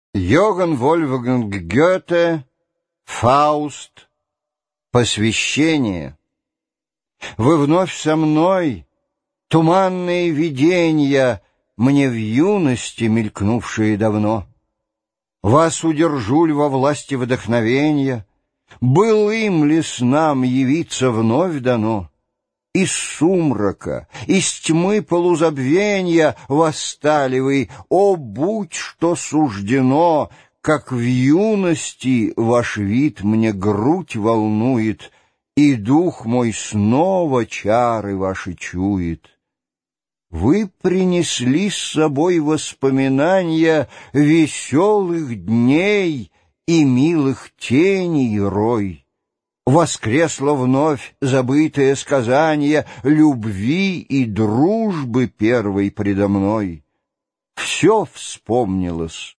Аудиокнига Фауст | Библиотека аудиокниг